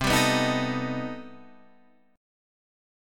C Minor Major 13th